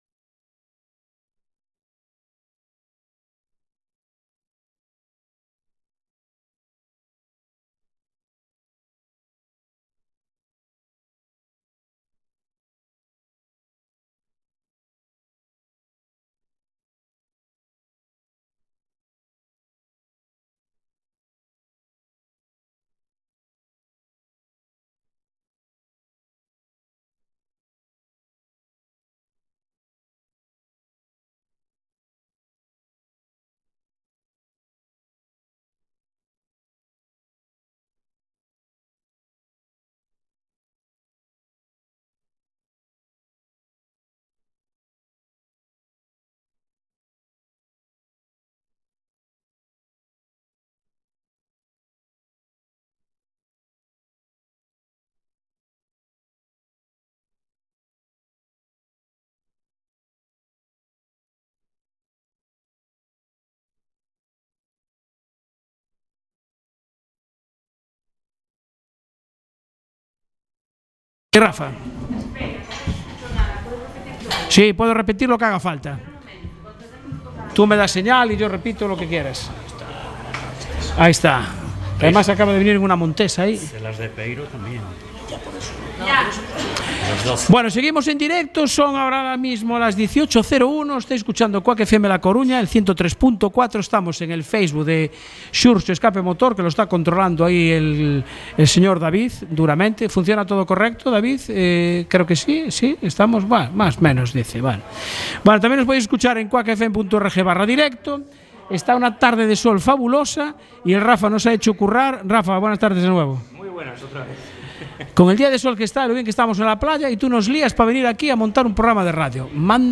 Programa Especial Exteriores SKPMotor sábado 13.04.24.